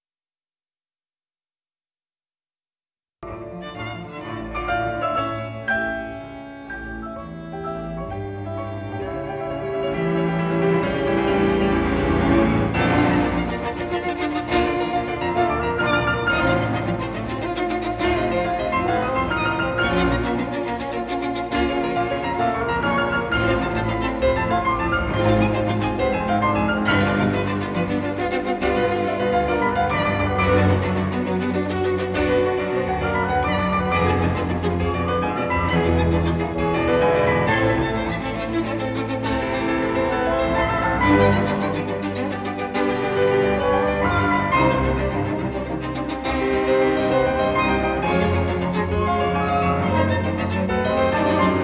Concert:
Theatre Accademico Bibiena
Classical music